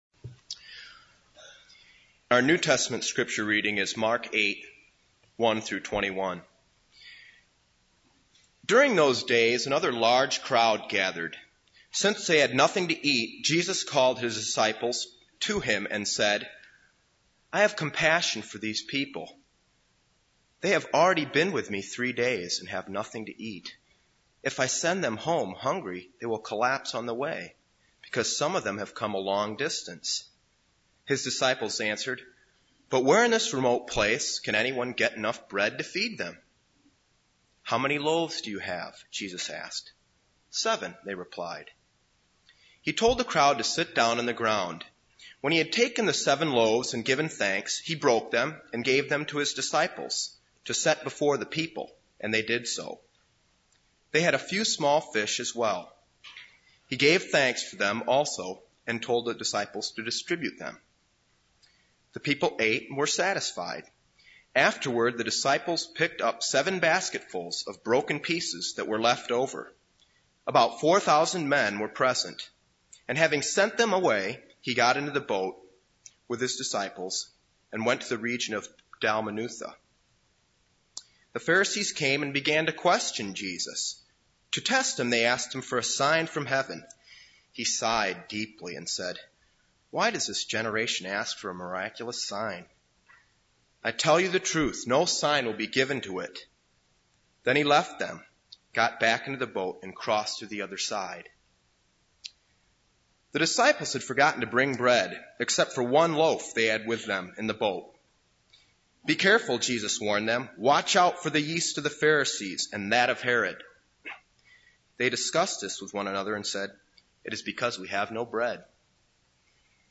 This is a sermon on Mark 8:1-21.